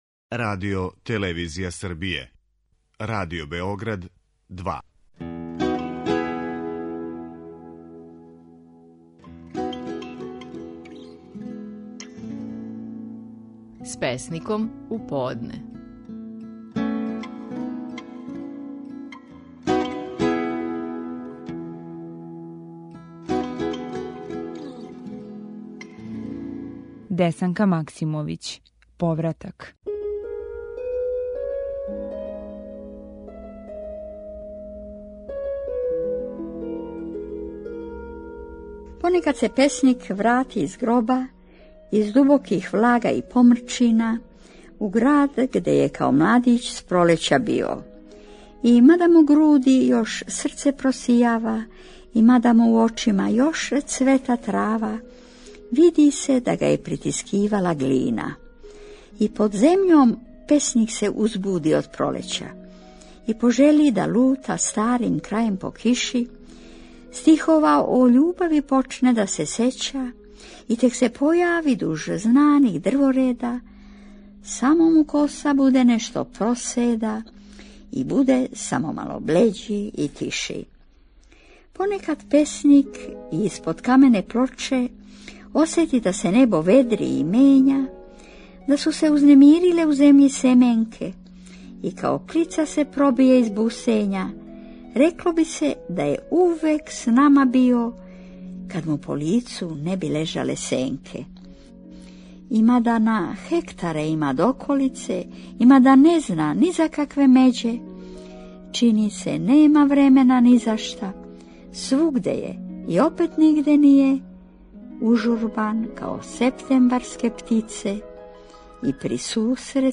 Стихови наших најпознатијих песника, у интерпретацији аутора.
Десанка Максимовић говори своју песму: „Повратак".